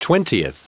Prononciation du mot twentieth en anglais (fichier audio)
Prononciation du mot : twentieth